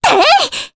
Mediana-Vox_Attack1_jp.wav